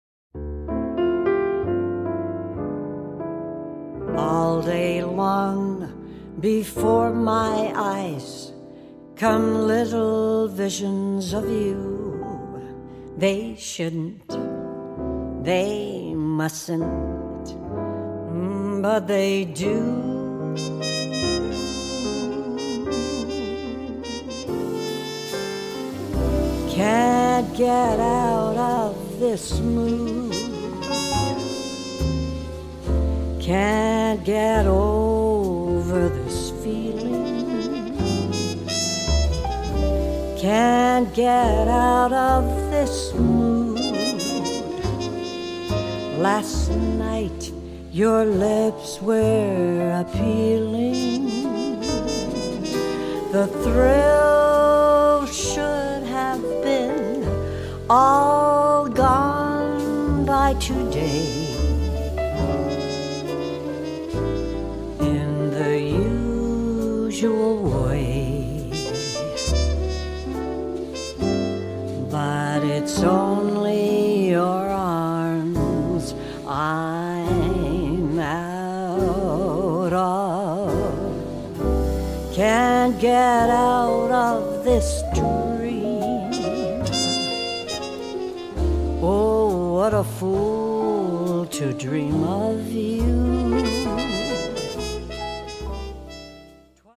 piano
bass
trumpet and flugelhorn
guitar
tenor sa
tenor sax and flute
percussion